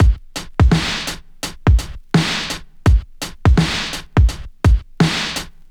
• 92 Bpm '00s Drum Groove A Key.wav
Free breakbeat sample - kick tuned to the A note. Loudest frequency: 950Hz
92-bpm-00s-drum-groove-a-key-1my.wav